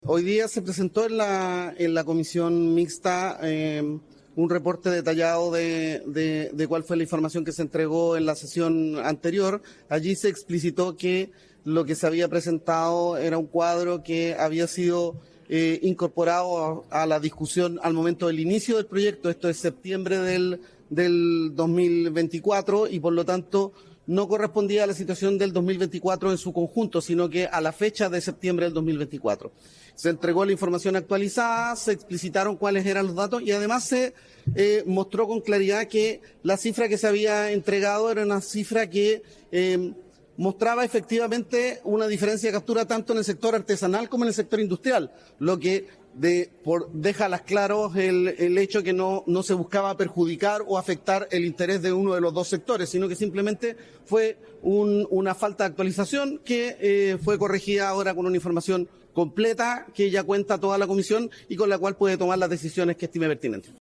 En tanto, respecto a la información desactualizada que se presentó por parte de la Subsecretaría de Pesca, el subsecretario explicó a la prensa que en esta última sesión se presentó un reporte detallado con las cifras del 2024 al momento de iniciar el debate (septiembre) y las cifras totales de captura de ese año, enfatizando que las cifras parciales que entregaron no eran tendenciosas, ya que reflejaban el total de la captura de merluza del sector industrial y artesanal.